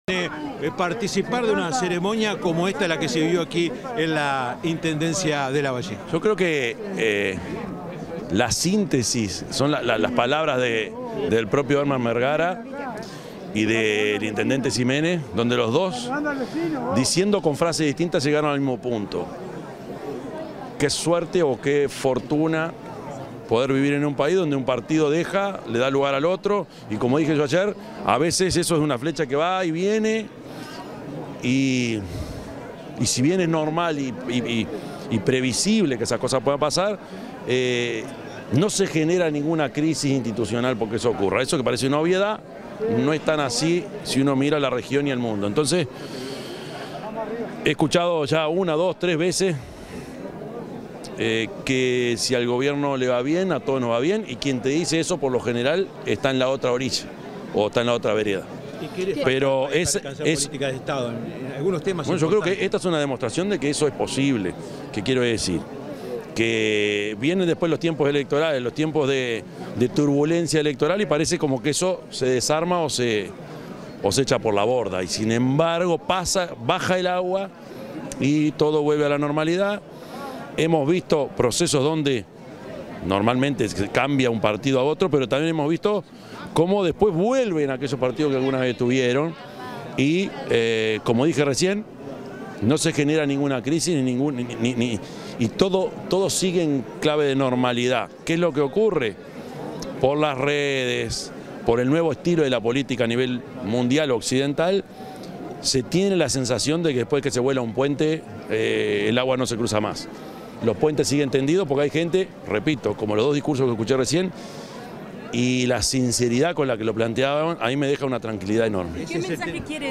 Tras la asunción del intendente de Lavalleja, Daniel Ximénez, el presidente de la República, Yamandú Orsi, realizó declaraciones a la prensa.